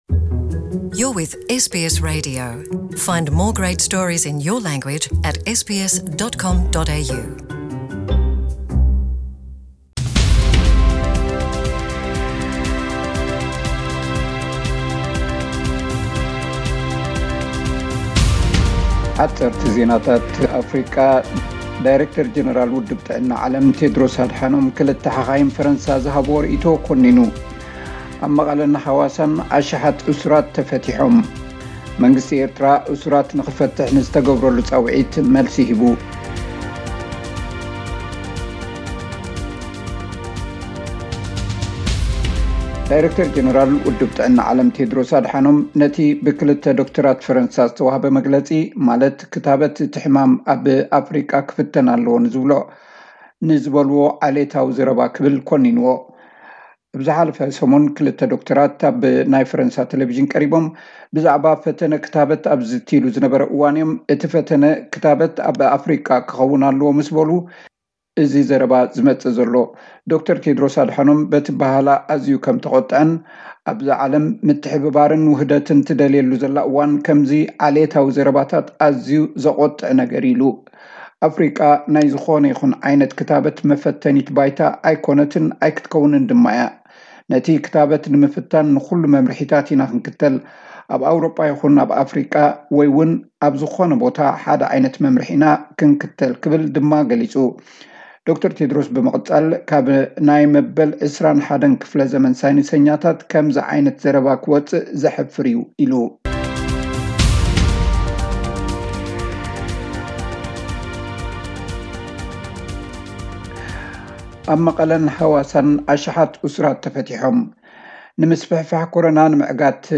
መንግስቲ ኤርትራ እሱራት ንኽፈትሕ ንዝተገብረሉ ጻዊዒት መልሲ ሂቡ:: (ሓጸርቲ ጸብጻብ)